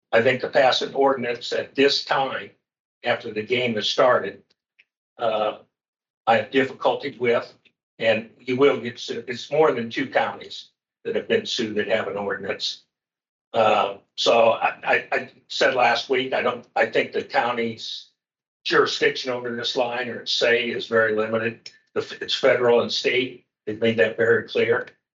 Supervisor Brian Johnson doesn’t want to happen in Guthrie County so he doesn’t expect even passing an ordinance against it